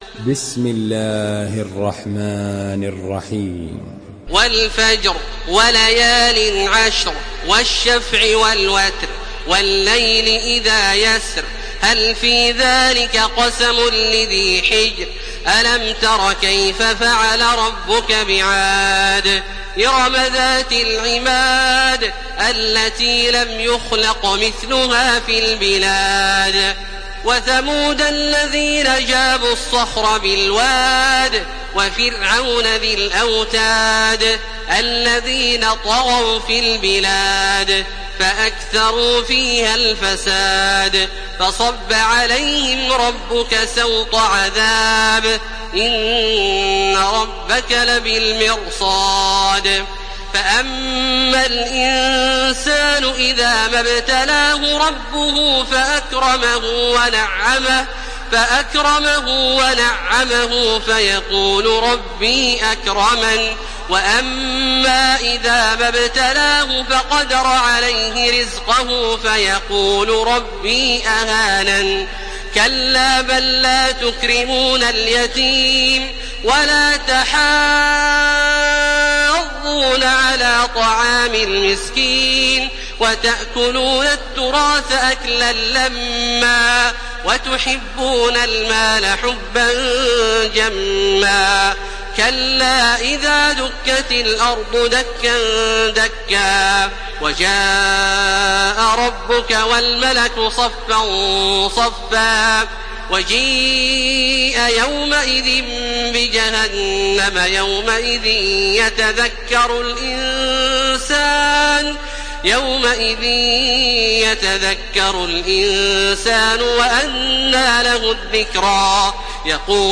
Surah Fecr MP3 by Makkah Taraweeh 1431 in Hafs An Asim narration.
Murattal